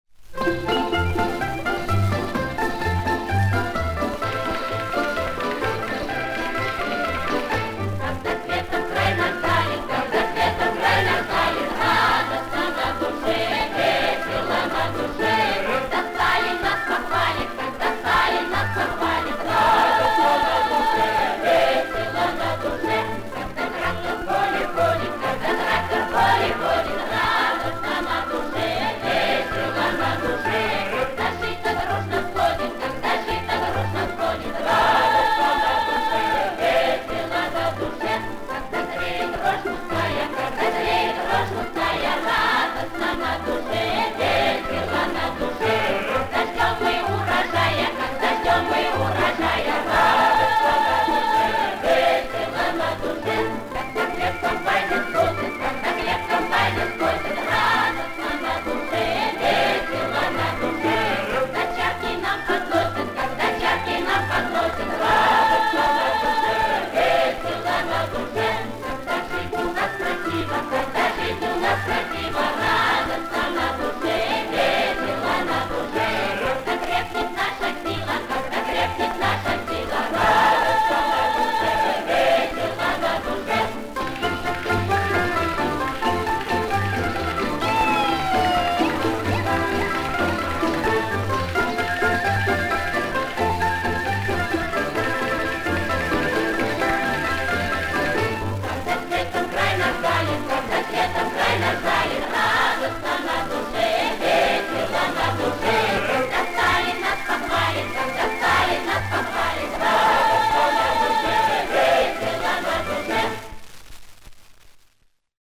Советская народная песня Воронежской области.